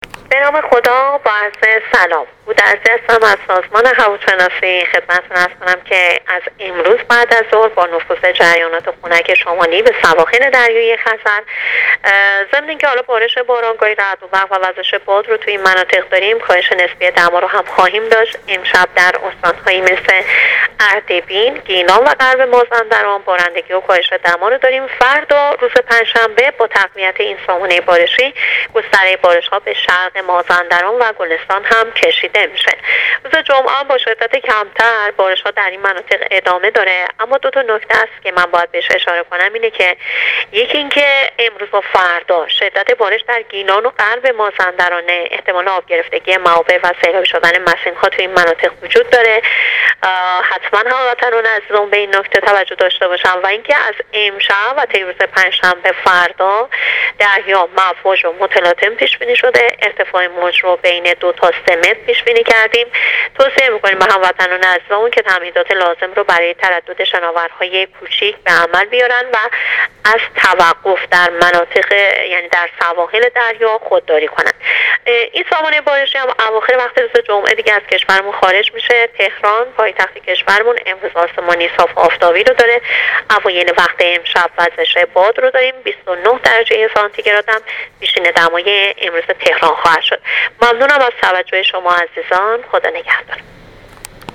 رادیو اینترنتی پایگاه خبری وزارت راه و شهرسازی: